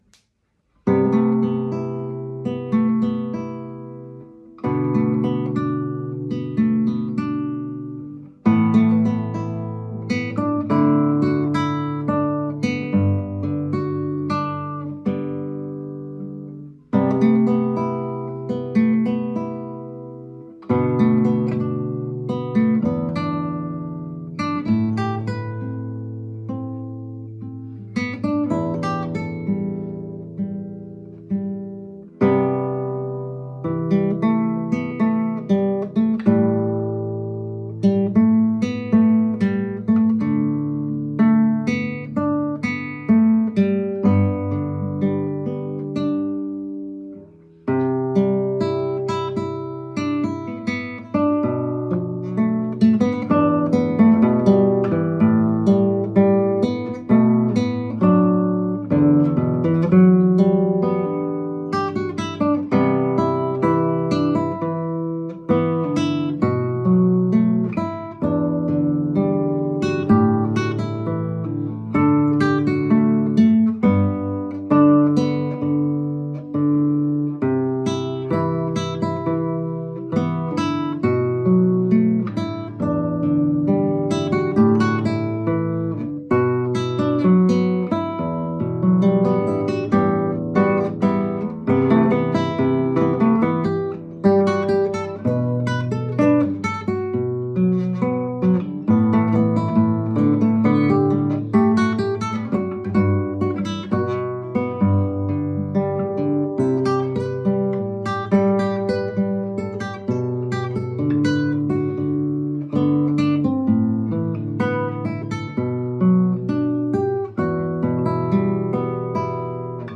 My solo guitar arrangements